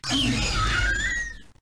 Звуки раптора
звук с визгом раптора